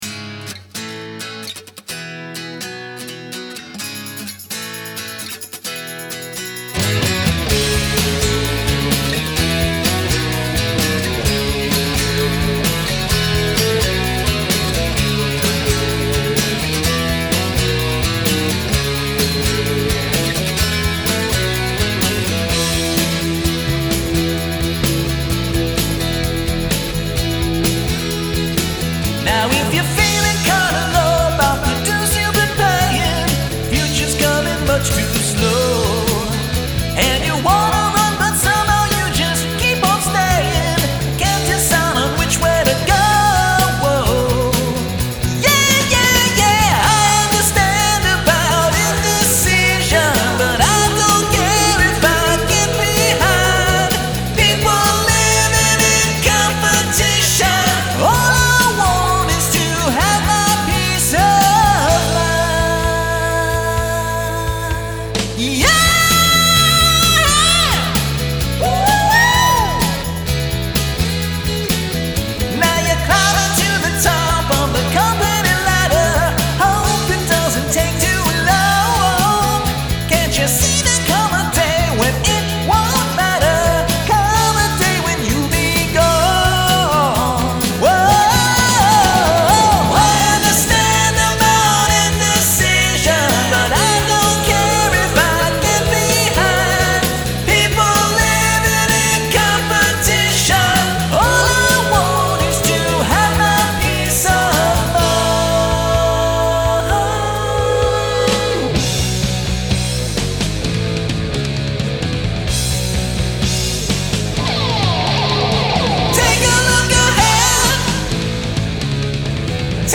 2. Another quick cover, “